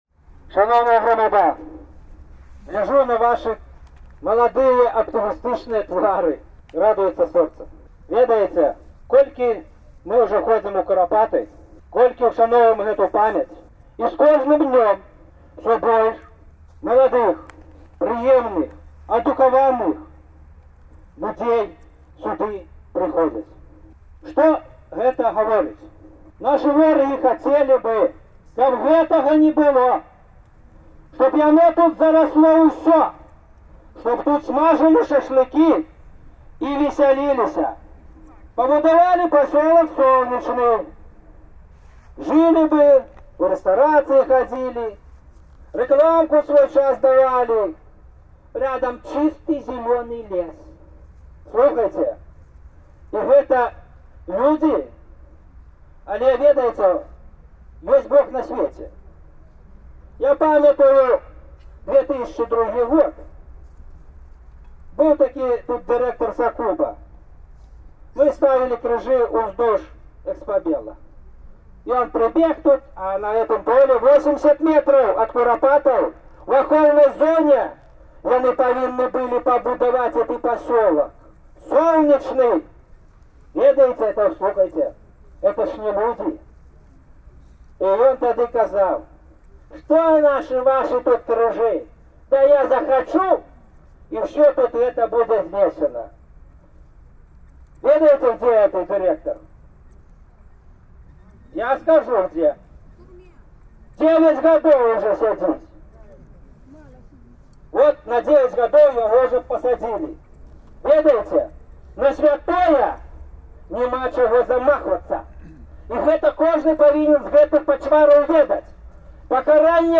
выступае ў Курапатах